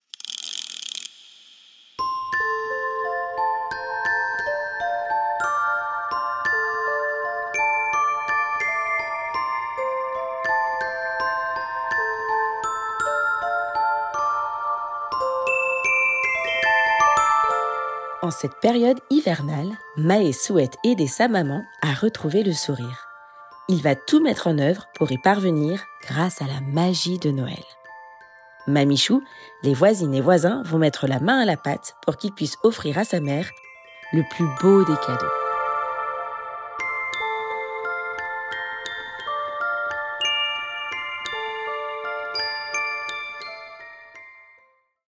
Le plus : écouter l’histoire racontée par l’autrice grâce au QR code de l’audio inclus.